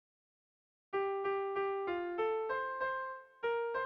Melodías de bertsos - Ver ficha   Más información sobre esta sección
ABDE